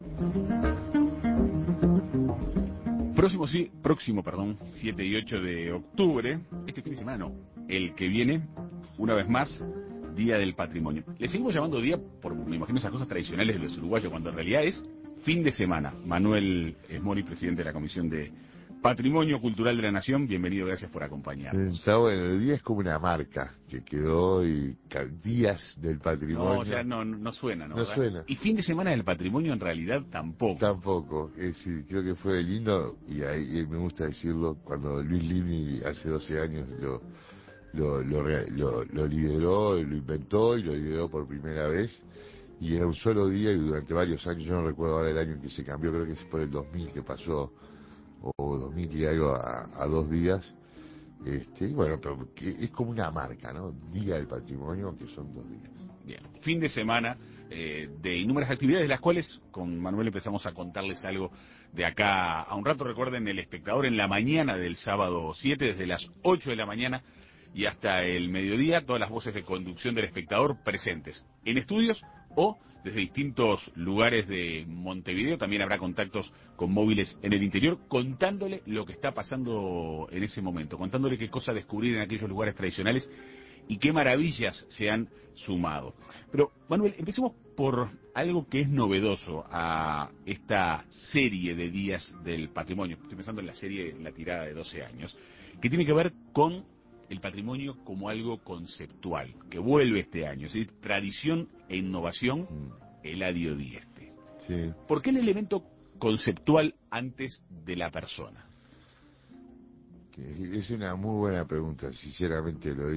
Entrevista con Manuel Esmoris, presidente de la Comisión de Patrimonio Cultural de la Nación